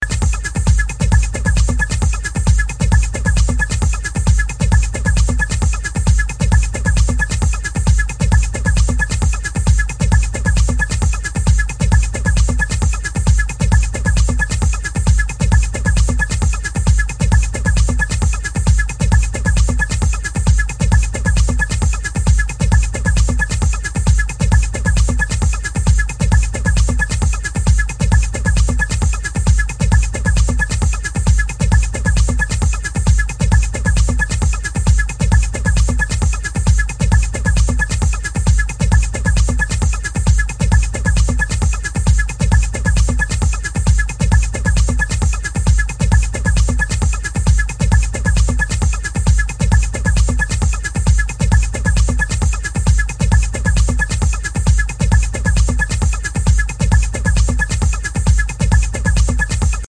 música Techno